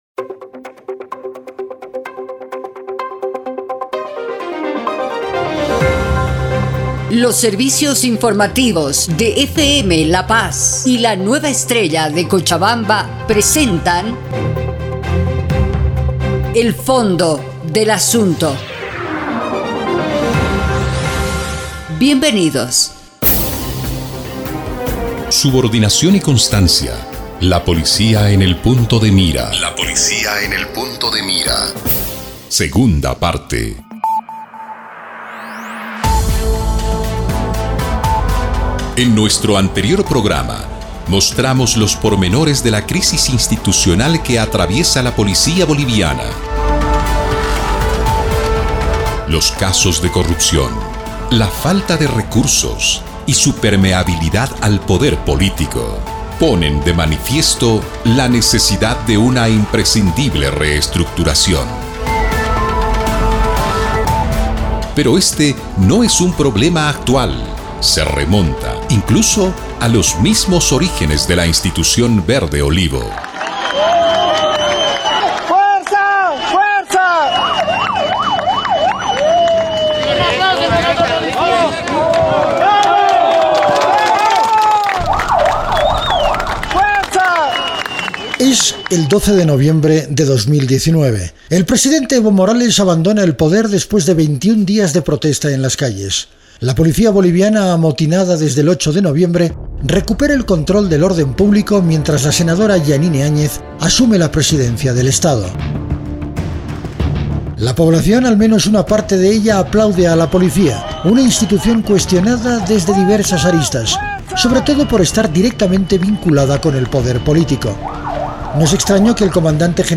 En FM La Paz 96.7 y Radio Estrella Cochabamba.